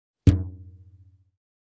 This category features high quality rubber sound effects. From the creaks and stretches of rubber objects to the satisfying snaps of rubber bands.
Elastic-rubber-band-pluck-3.mp3